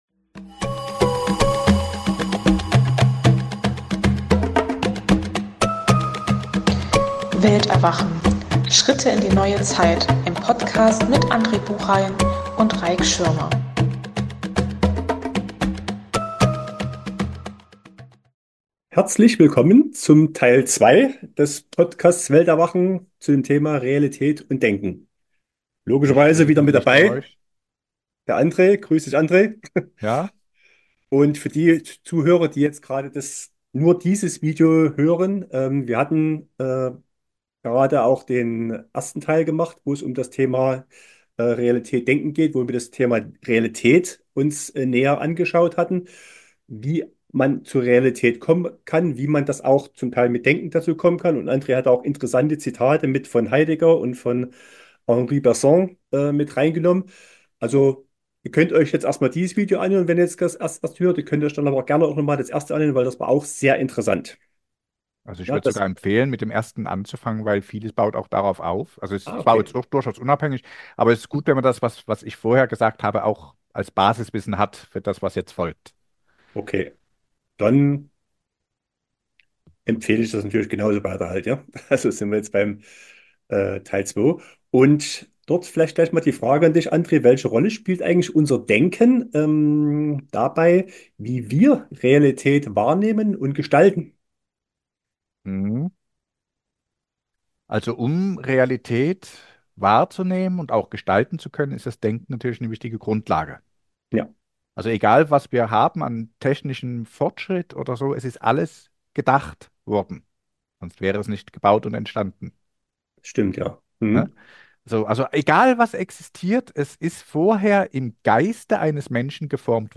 Beschreibung vor 1 Jahr In einem lockeren Gespräch wird das Thema "Realität & Denken" behandelt und wichtige Aspekte / Ansichten dazu erläutert, sowie sehr hilfreiche Zitate von Philosophen eingespielt.